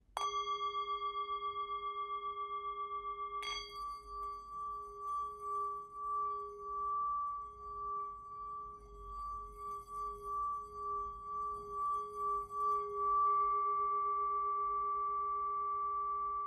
Tibetská mísa Gyal malá
Tepaná tibetská mísa Gyal o hmotnosti 420 g, včetně paličky.
tibetska_misa_m51.mp3